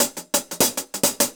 Index of /musicradar/ultimate-hihat-samples/175bpm
UHH_AcoustiHatB_175-04.wav